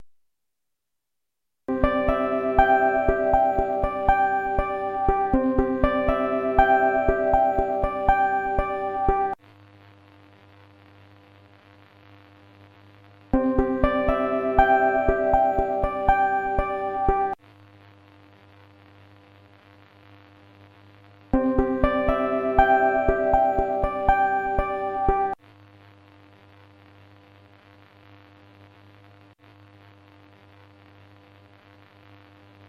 AMがモノラル 16kHz 24kbps 10Mbyte/h
ハードディスクを使っているから仕方の無いことかもしれませんが、AM FMとも録音ファ イルにノイズが混じってしまいます。(
AM ノイズサンプル (録音されている放送の通常の音量がピアノの音だと思ってください。)